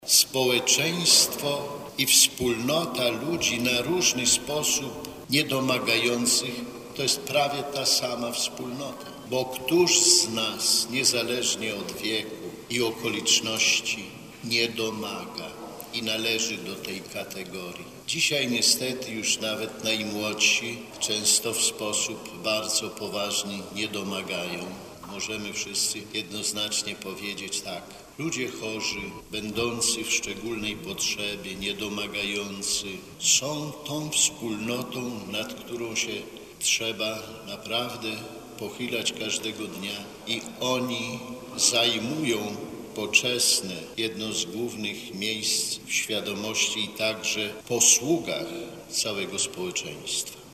Centralne obchody dzisiejszego dnia w diecezji warszawsko-praskiej odbyły się w Sanktuarium Matki Bożej z Lourdes przy ul. Wileńskiej.
O tym, jak bardzo ważną grupą w Kościele i w świecie są ludzie chorzy, mówił w homilii bp Romuald Kamiński, ordynariusz warszawsko-praski i przewodniczący Zespołu Konferencji Episkopatu Polski ds. Duszpasterstwa Służby Zdrowia.